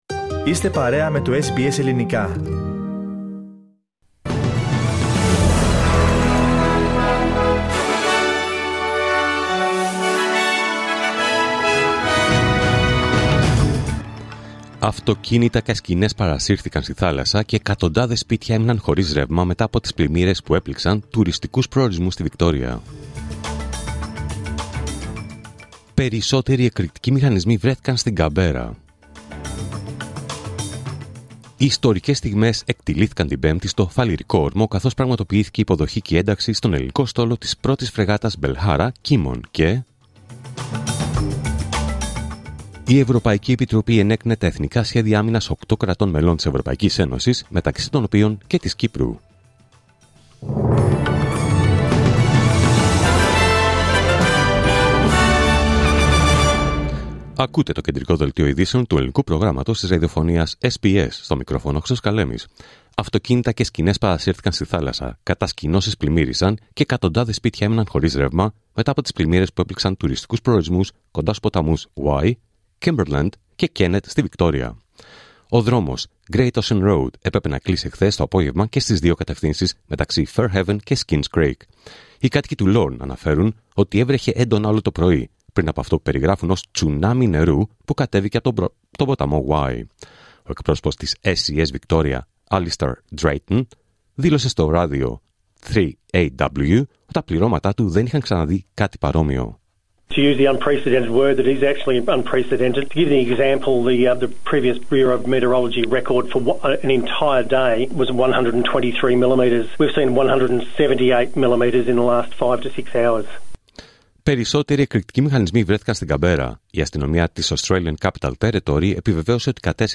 Δελτίο Ειδήσεων Παρασκευή 16 Ιανουαρίου 2026